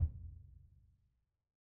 BDrumNewhit_v2_rr2_Sum.wav